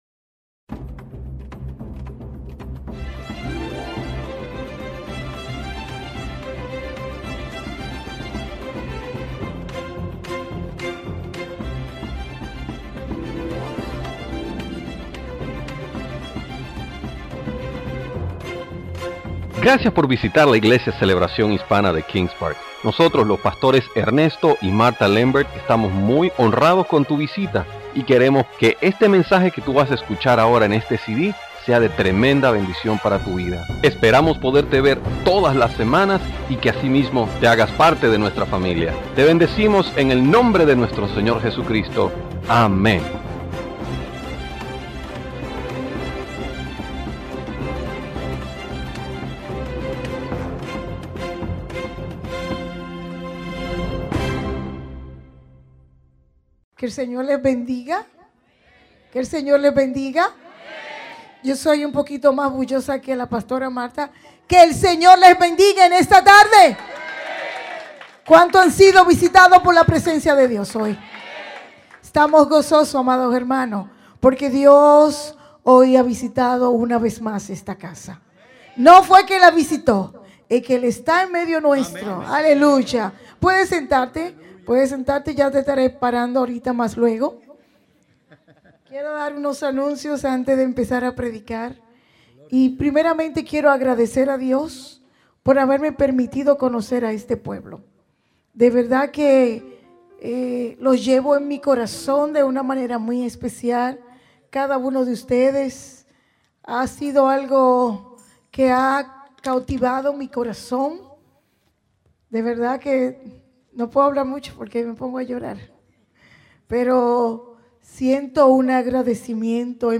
Servicio Dominical Predicadores